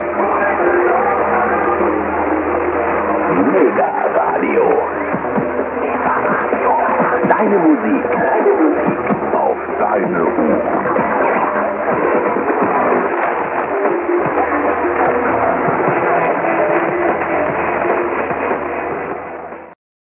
station identification of Mega Radio